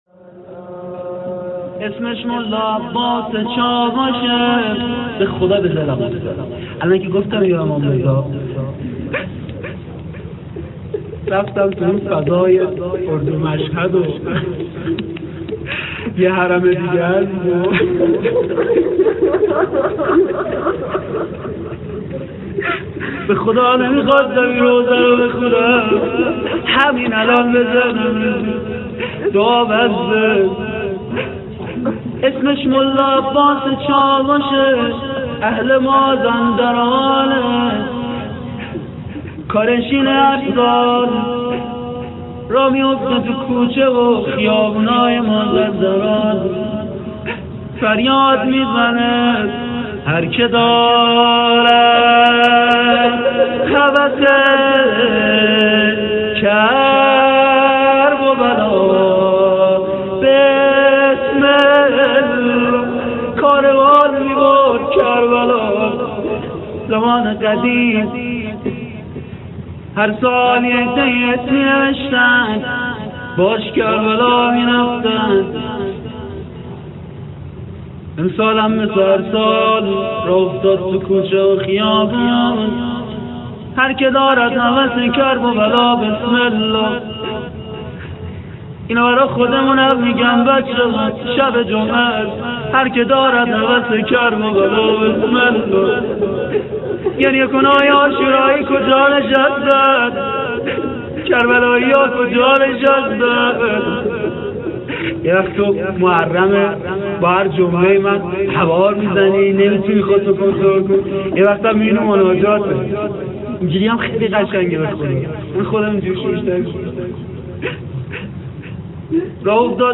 قسمت دوم روضه.mp3
قسمت-دوم-روضه.mp3